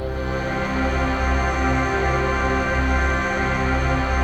ATMOPAD27 -LR.wav